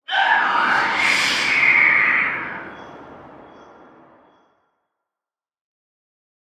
shriek5.ogg